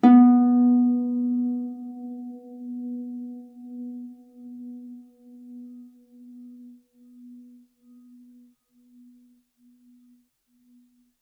KSHarp_B3_mf.wav